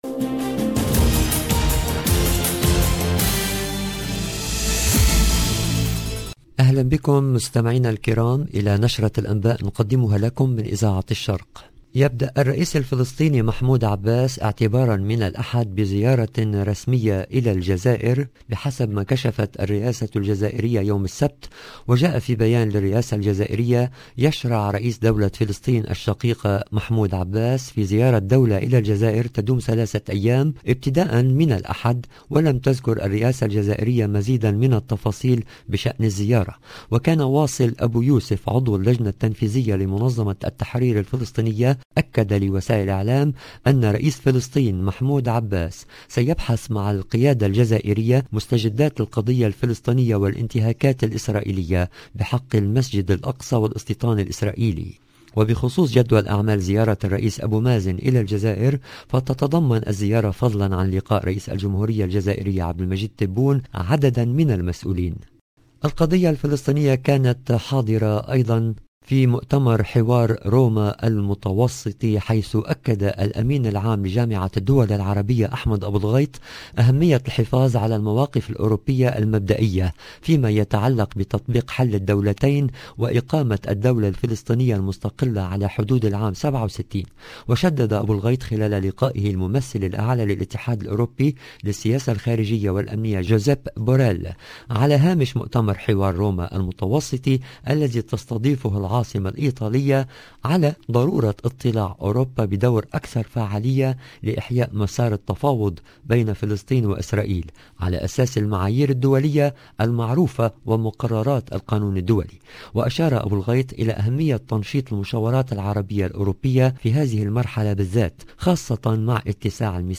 LE JOURNAL DU SOIR EN LANGUE ARABE DU 5/12/2021